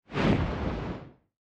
fissure-explosion-5.ogg